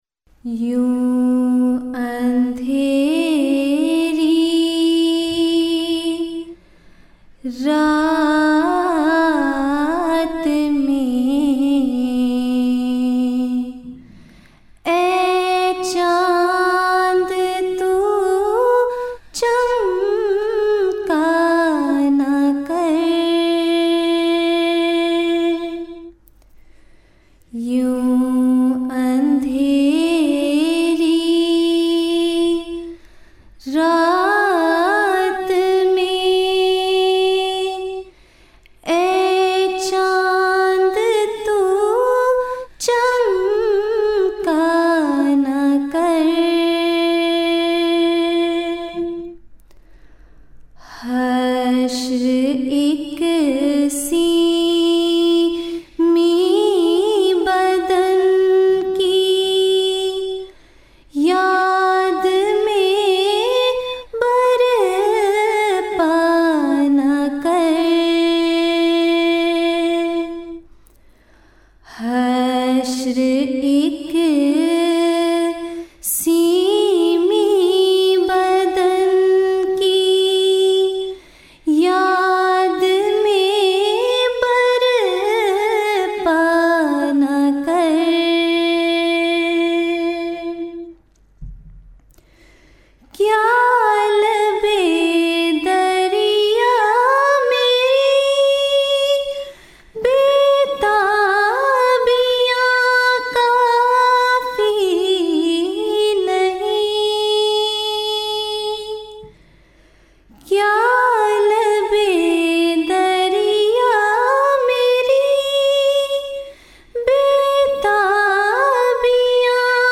Urdu Poems
Voice: Member Lajna Ima`illah
MTA 2006 (Jalsa UK)